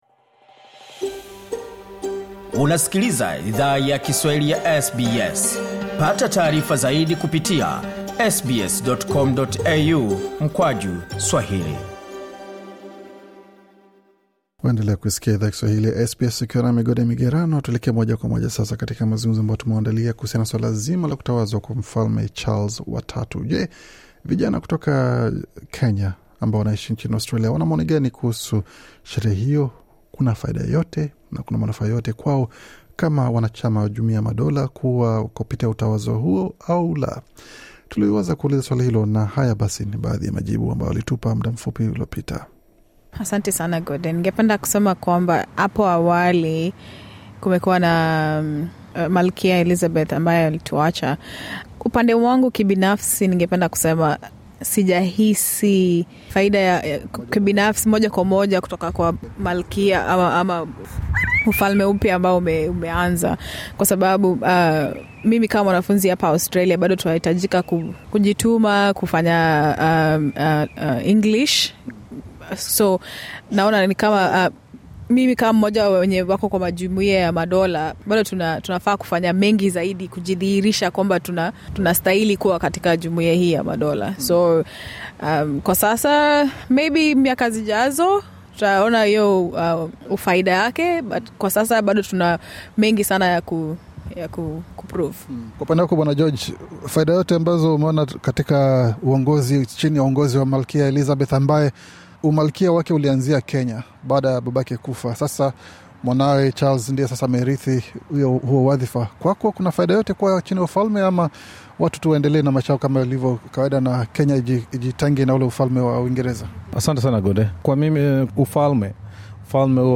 Katika mazungumzo maalum na SBS Swahili, baadhi yao waliweka wazi hisia zao kuhusu hafla hiyo pamoja na masaibu wanayo pitia ughaibuni licha yakuwa wanatoka katika nchi wanachama wa jumuiya yamadola.